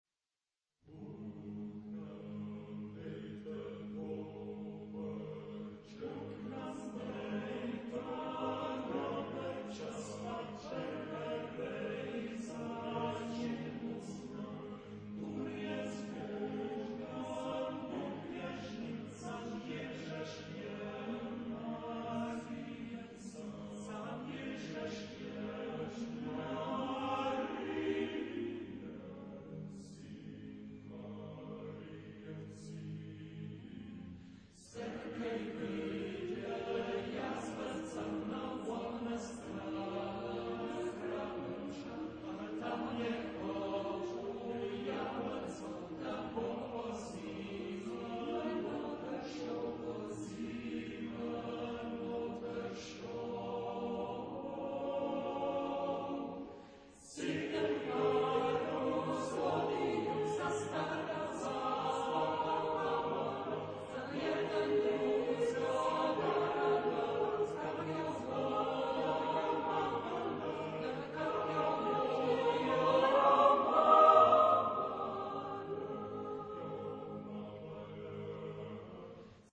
Genre-Style-Form: Secular ; Folk music
Mood of the piece: lively
Type of Choir: SSAATTBB  (8 mixed voices )